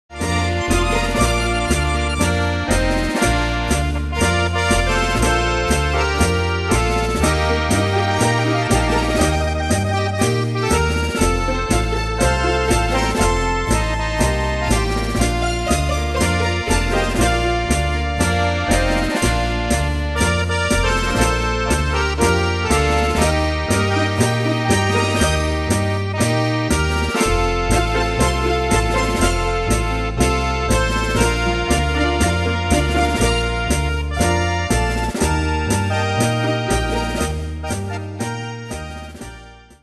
Demos Midi Audio
Danse/Dance: Tango Cat Id.
Pro Backing Tracks